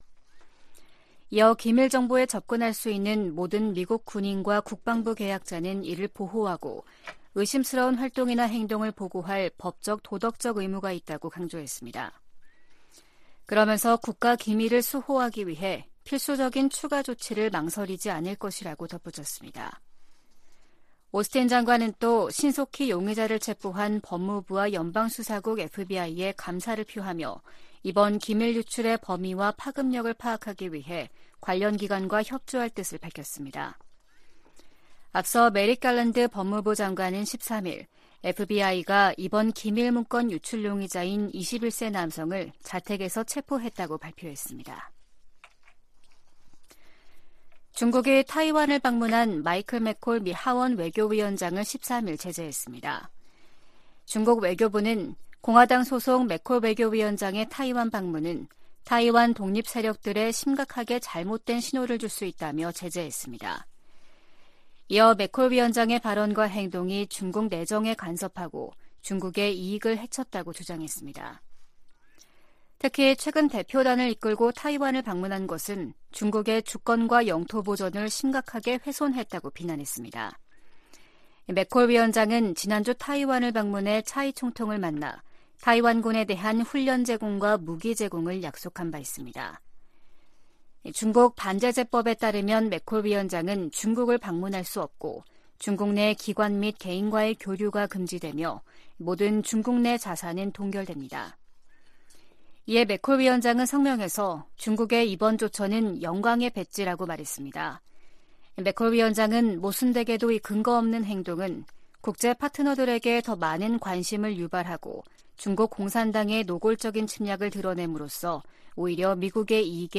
VOA 한국어 아침 뉴스 프로그램 '워싱턴 뉴스 광장' 2023년 4월 15일 방송입니다. 북한은 13일 발사한 '화성포-18형'이 고체연료를 사용한 신형 대륙간탄도미사일(ICBM)이라고 다음날인 14일 밝혔습니다. 북한이 핵 공격을 감행하면 김정은 정권의 종말을 초래할 것이라고 미국과 한국 국방당국이 경고했습니다. 북한이 우주 사업을 적극 추진하겠다고 밝힌 데 대해 미 국무부는 안보리 결의 위반 가능성을 지적했습니다.